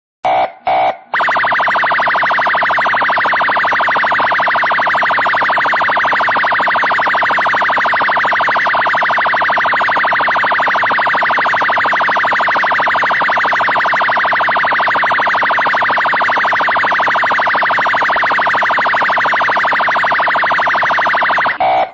Sirene da polícia Rota
Sirene da Polícia Militar Sirene de Polícia
Categoria: Sons de sinos e apitos
Descrição: O áudio é de alta qualidade e perfeito para dar um toque único ao seu aparelho.
sirene-da-policia-rota-pt-www_tiengdong_com.mp3